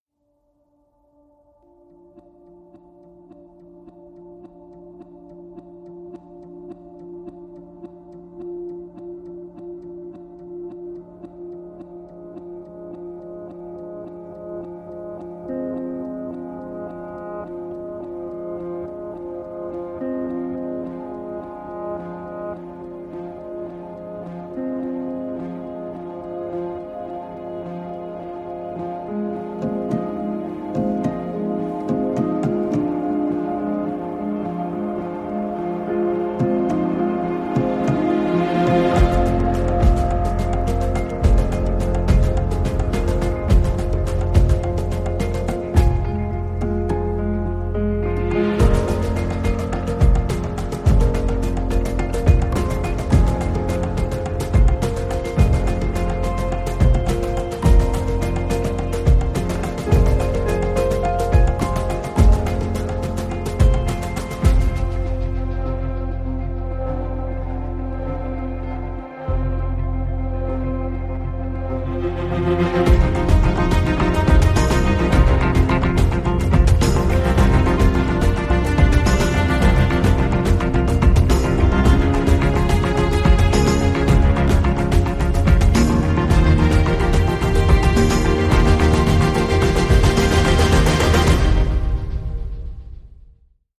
piano, ensemble à cordes et discrètes sonorités électro